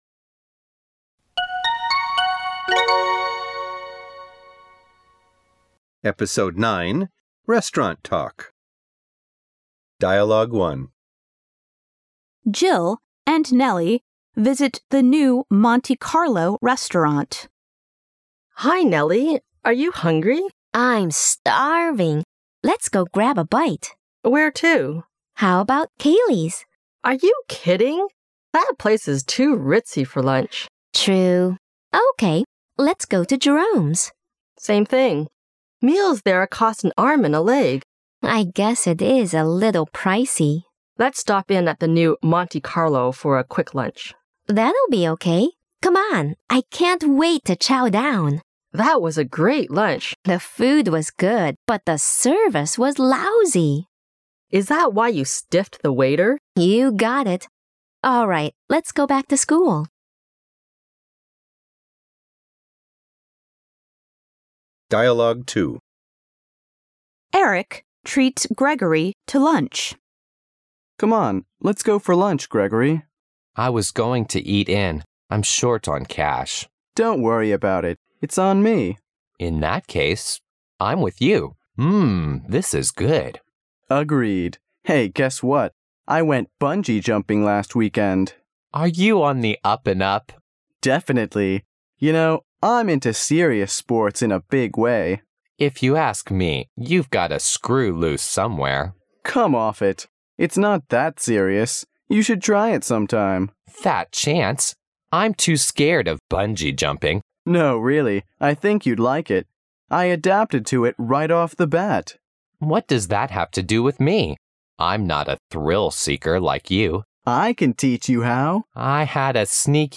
Dialogue One :Joe and Nally visit the new Multicolor Restaruant
Dialogue Two : Eric treats Gregory to lunch